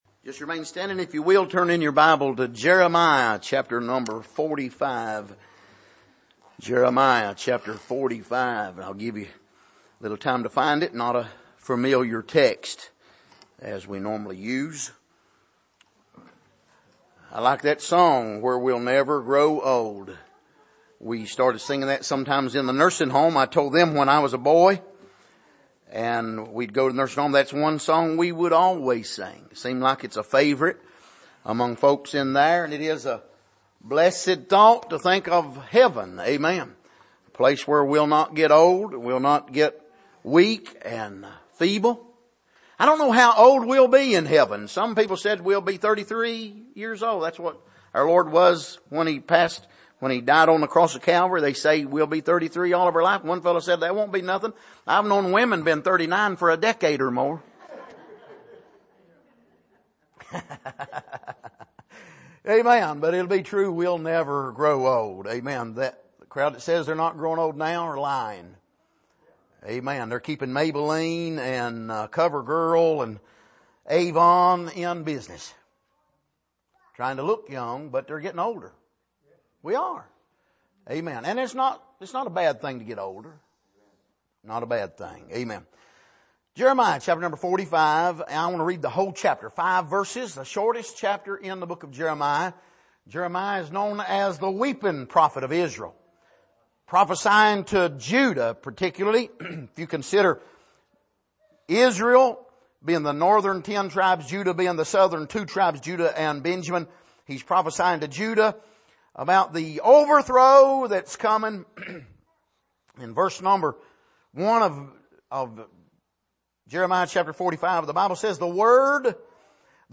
Passage: Jeremiah 45:1-5 Service: Sunday Morning